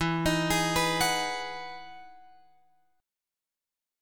E Major 9th